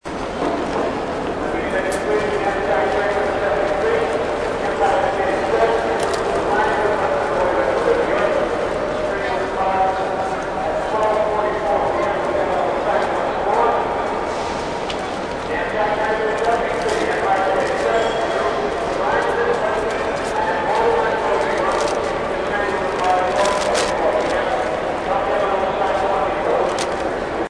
ESTACION TREN CENTRAL AMBIENTE
EFECTO DE SONIDO DE AMBIENTE de ESTACION TREN CENTRAL AMBIENTE
estacion_tren_Central-ambiente.mp3